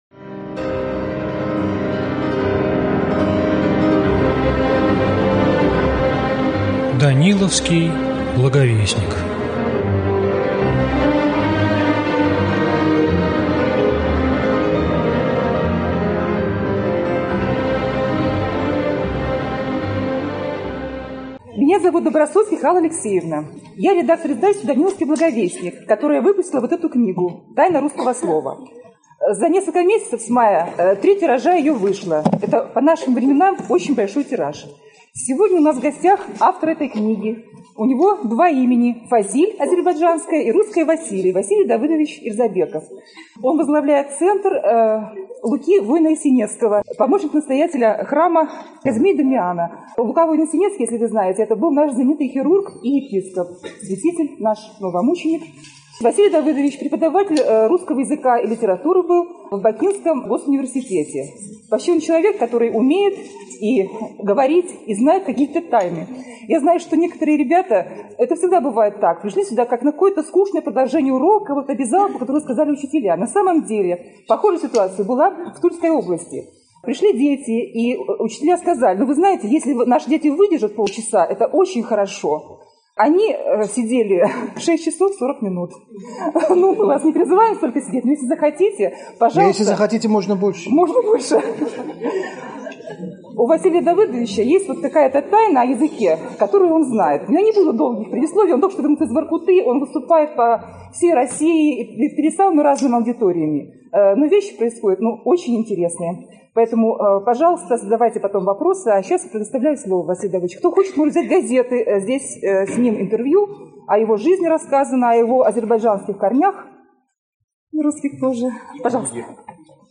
Аудиокнига Тайна русского слова | Библиотека аудиокниг